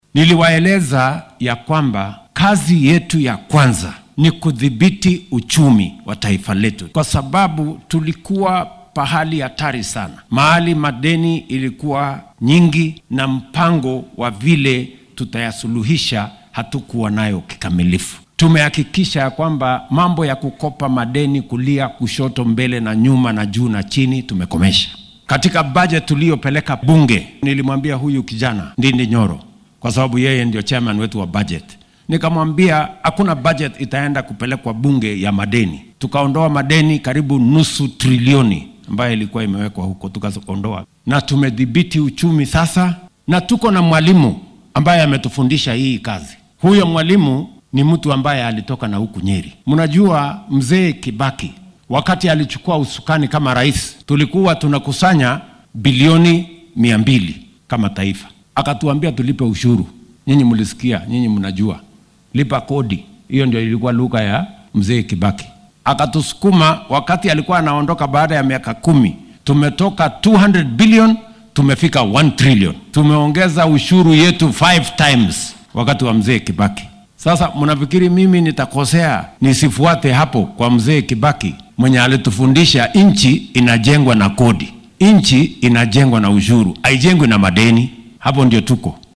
Ruto ayaa xilli uu munaasabad duco ah ku qabtay xarunta madaxtooyada yar ee Sagana State Lodge ee ismaamulka Nyeri difaacay canshuuraha dheeraadka ah ee la soo rogay.